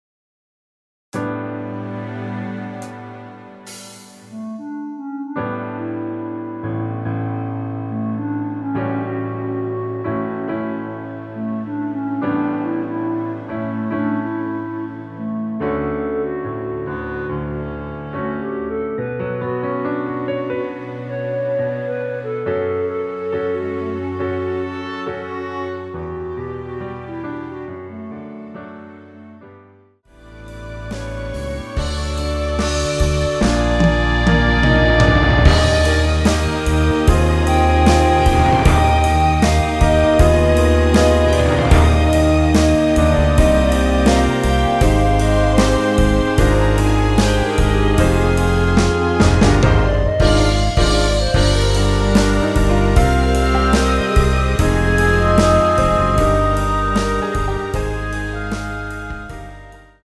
◈ 곡명 옆 (-1)은 반음 내림, (+1)은 반음 올림 입니다.
앞부분30초, 뒷부분30초씩 편집해서 올려 드리고 있습니다.
중간에 음이 끈어지고 다시 나오는 이유는
뮤지컬